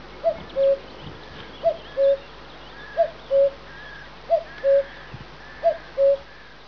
With stunning reproducibility of date, the first Cuckoo of the year was heard.
Call (73K)
cuckoo.wav